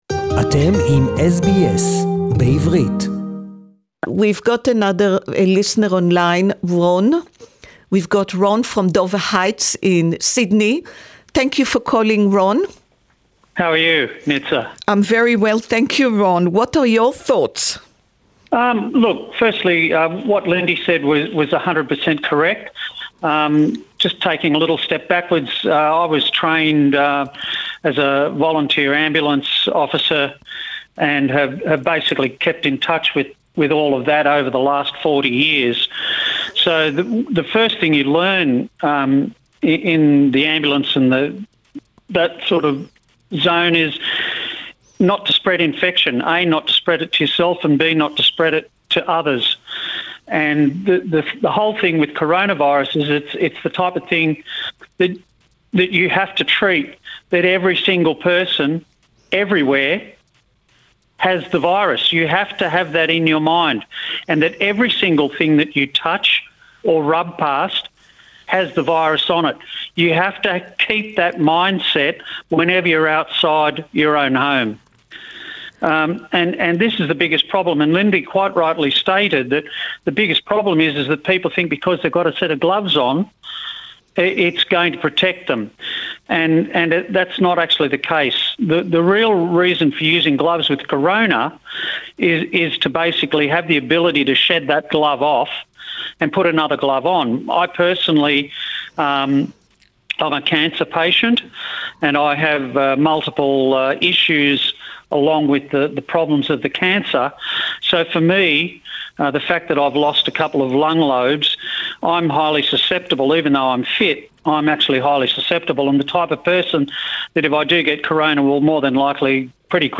English Talk-back)12.4.2020